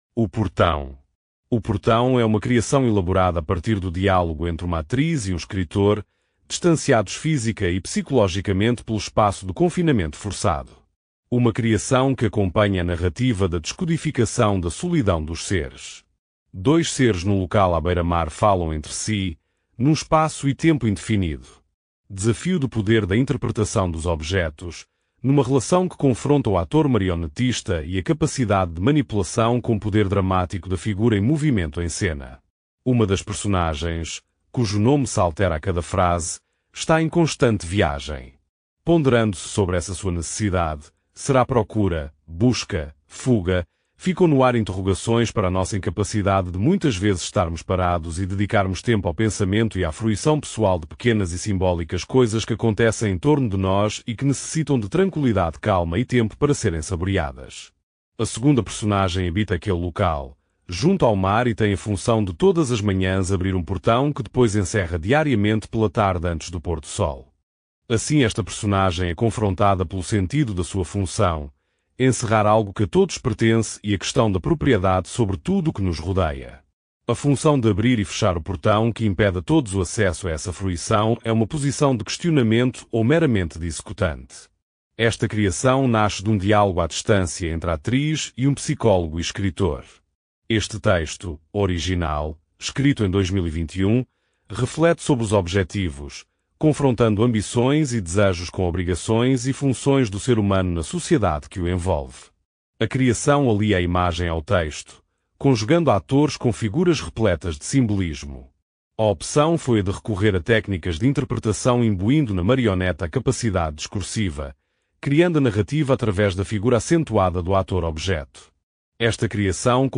este áudio guia possui 13 faixas e duração de 00:19:08, num total de 13.6 Mb